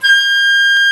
FLT FL G6.wav